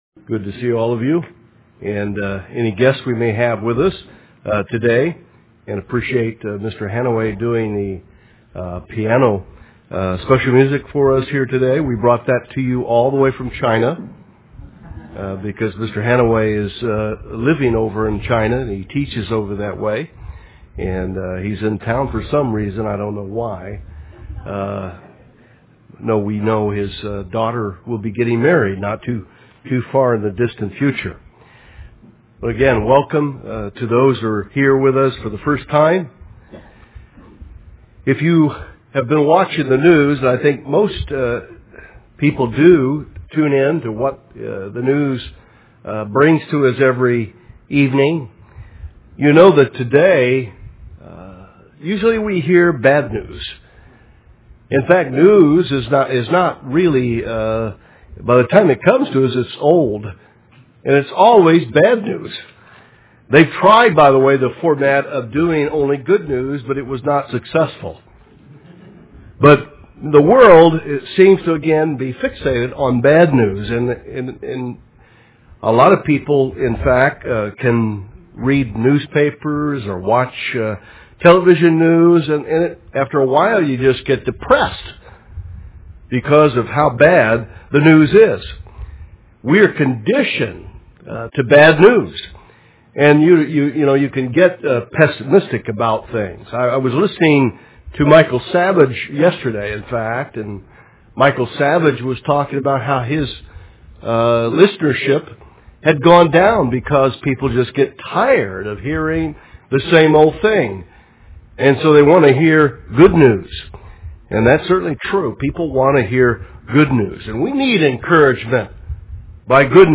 The Kingdom of God is a central feature and fundamental teaching of Jesus Christ as found in the Gospels. Today in this Kingdom of God seminar, we cover some of the essential features as found in the Holy Bible.
UCG Sermon Transcript This transcript was generated by AI and may contain errors.